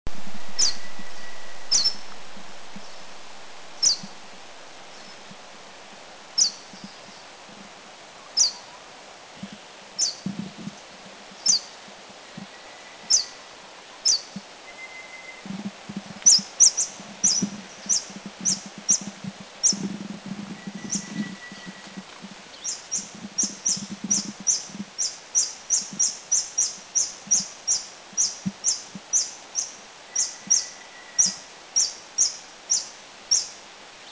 Humes Warbler